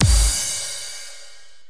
soft-hitfinish3.ogg